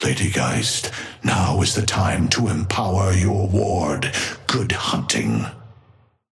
Patron_male_ally_ghost_oathkeeper_5a_start_02.mp3